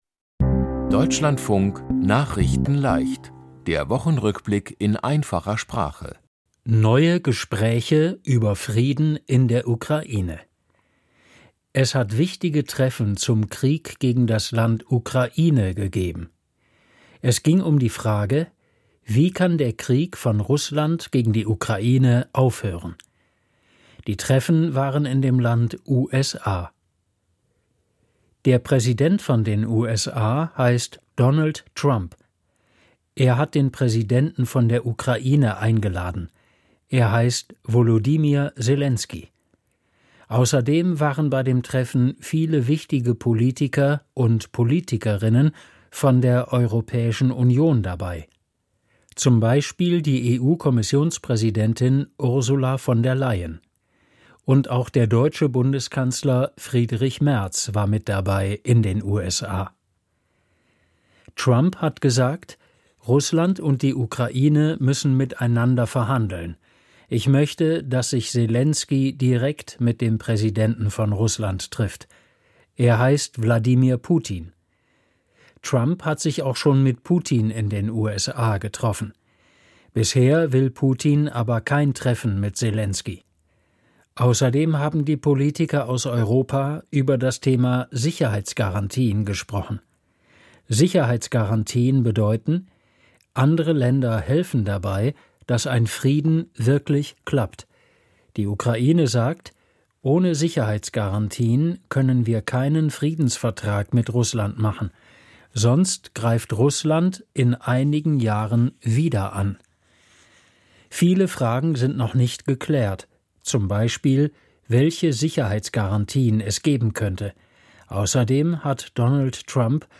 Die Themen diese Woche: Neue Gespräche über Frieden in der Ukraine, Israel will im West-Jordan-Land viele neue Wohnungen für jüdische Siedler bauen, Gewalt bei Demonstrationen im Land Serbien, Früherer Verkehrs-Minister Andreas Scheuer angeklagt, Komplette Kirche in Schweden umgezogen, Die Computer-Spiele-Messe Gamescom hat begonnen, Feuerwehr aus Deutschland hilft im Land Spanien und Deutschland ist Europa-Meister im Hockey der Männer. nachrichtenleicht - der Wochenrückblick in einfacher Sprache.